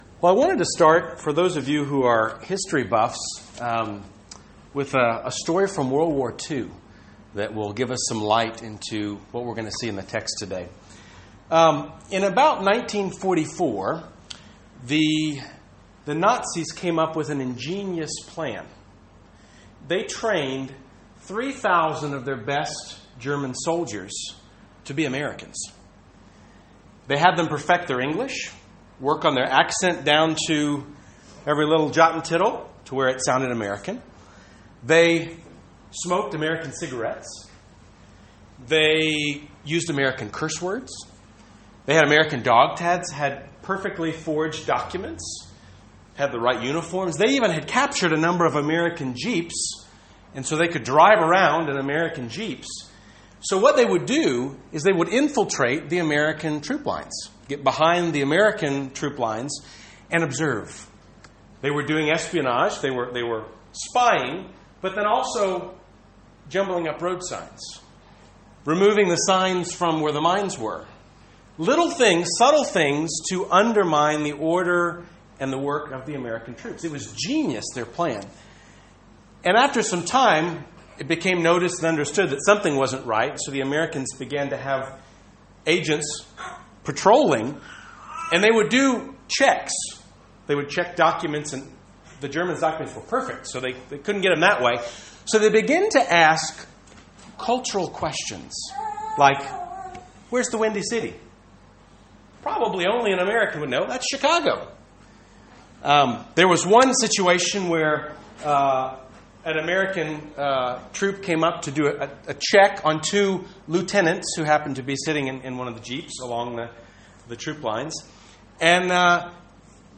Bible Text: Judges 21 | Predicatore/Preacher